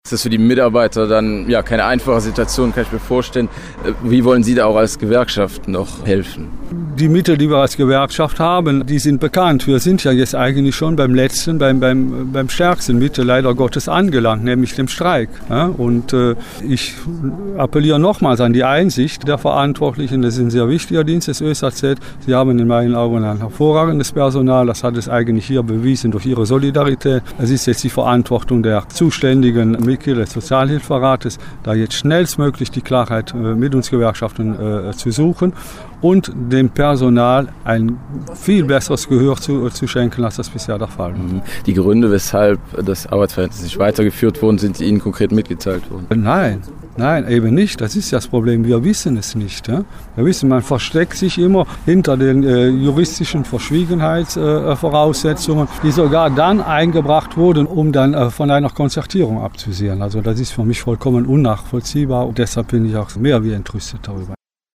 GrenzEcho-Interview